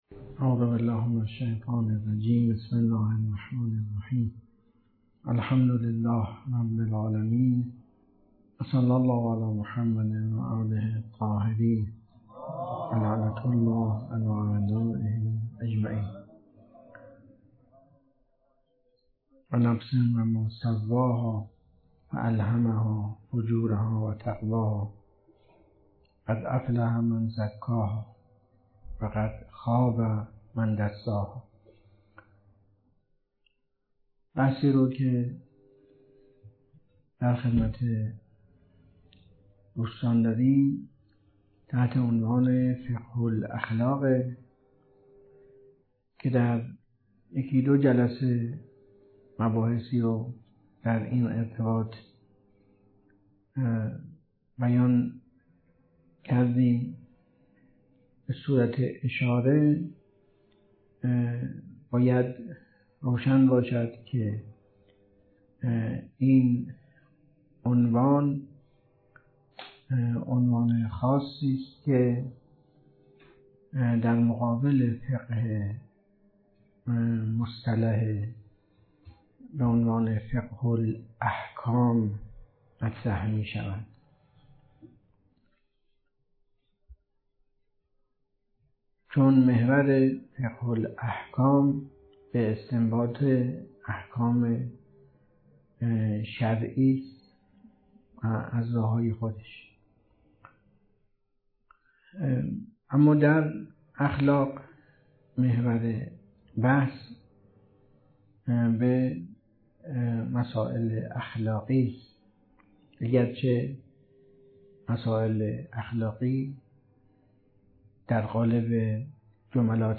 درس خارج فقه الاخلاق جلسه سوم